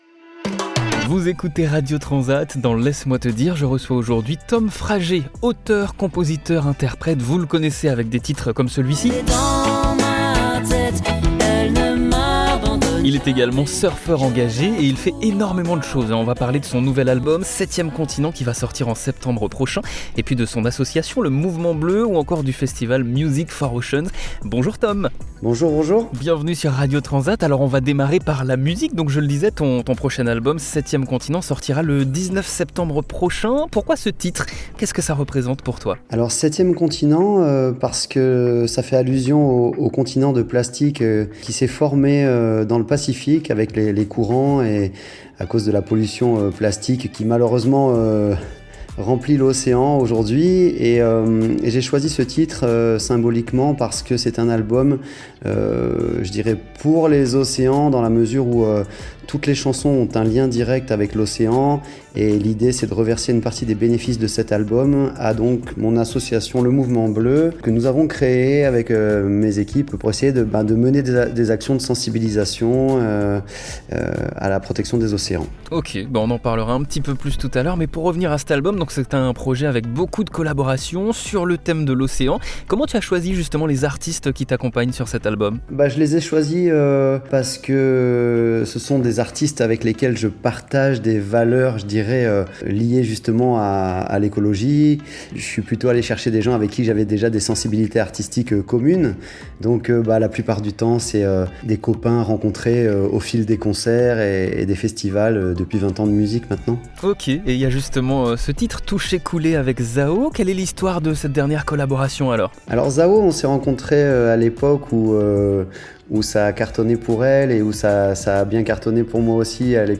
Dans cette interview, Tom Frager nous présente son prochain album à venir, "7è continent", dont une partie des bénéfices iront à son association "Le Mouvement bleu" qui œuvre pour la protection des océans.